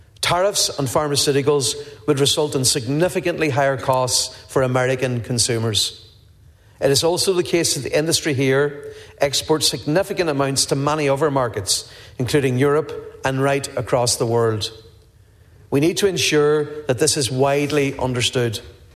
Donegal Deputy Padraig MacLochlainn told the Dail today it’s important that the public have a clear understanding on how tariffs work………..